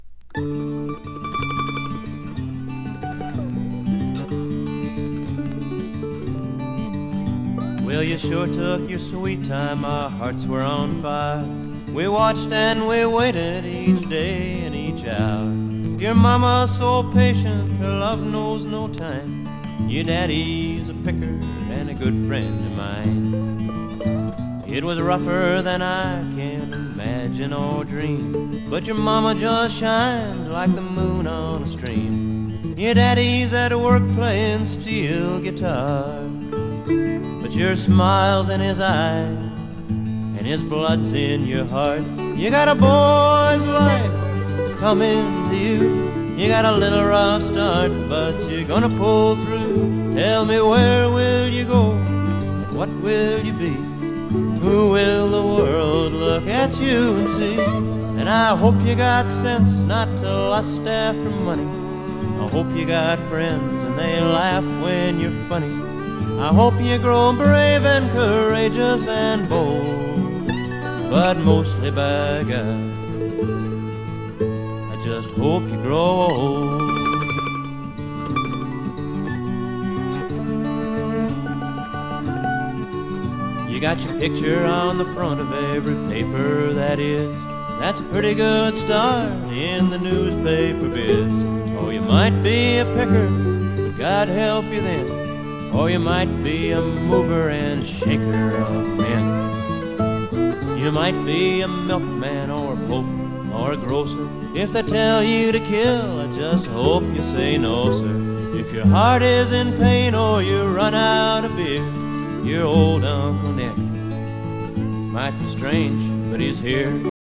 カントリー〜フォーク・アルバムですが、時々アコースティック・スウィングをやったりしてます。